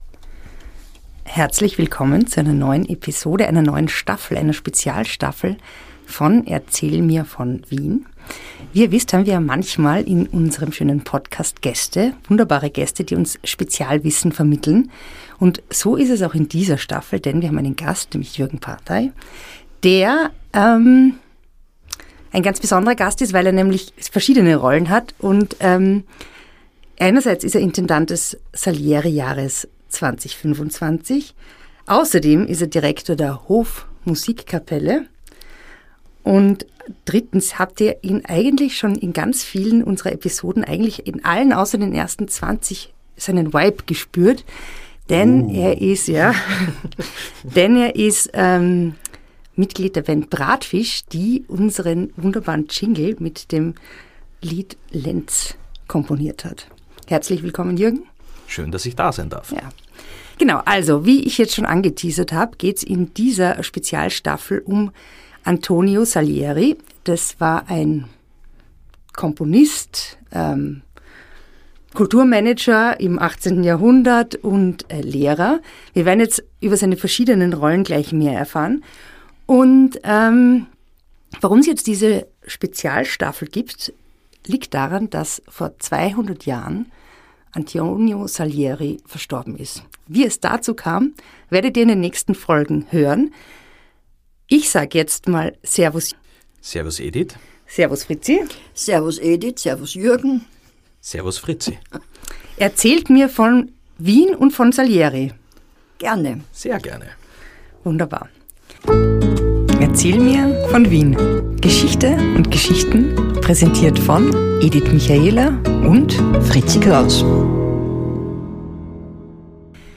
Die beiden spazieren durch Wien und unterhalten sich über bekannte und unbekannte Orte, prägende Persönlichkeiten und die vielen kuriosen Geschichten, die es an allen Ecken, in allen Grätzeln und Bezirken Wien zu entdecken gibt.